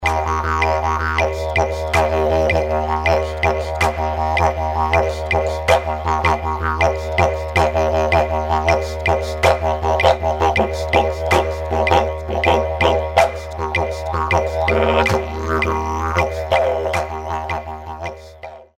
Practice Rhythms for Compound Meters
CLICK HERE quick 10/8 rhythm